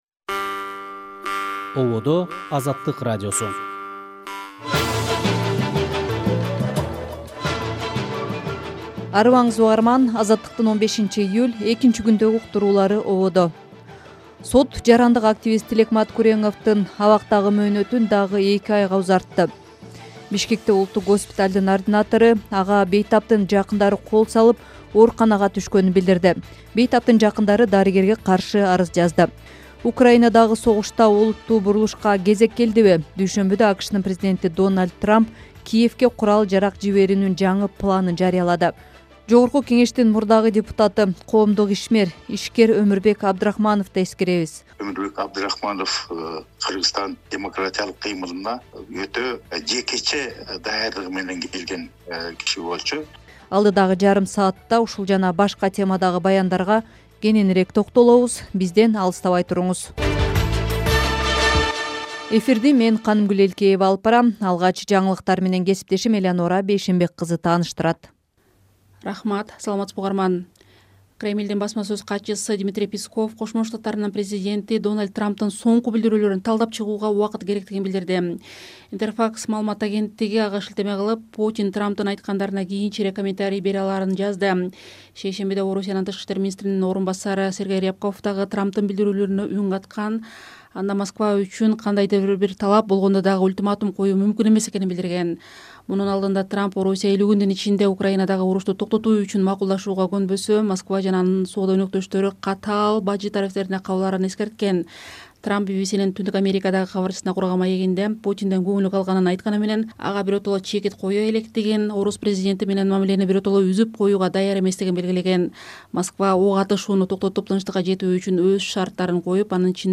Азаттык: Кечки радио эфир | 15.07.2025 | Трамптын 50 күндүк эскертүүсү.